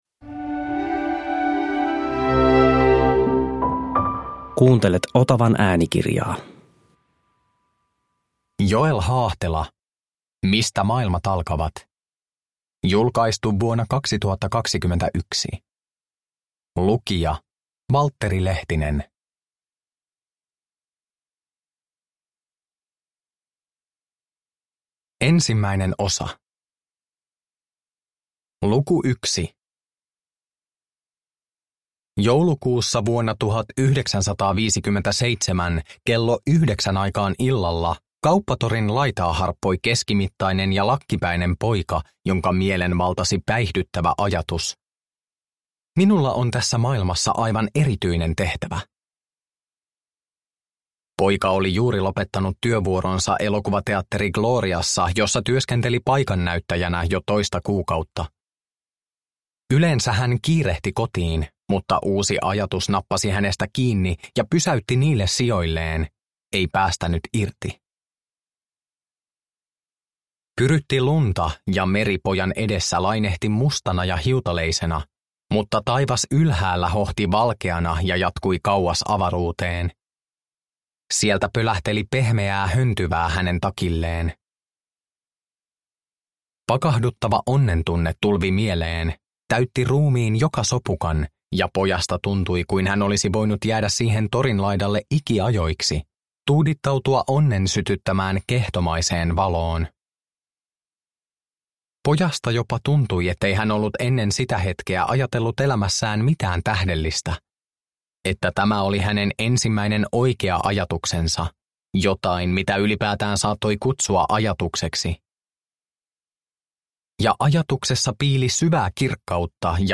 Mistä maailmat alkavat – Ljudbok – Laddas ner